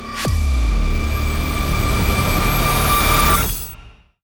reel_anticipation3.wav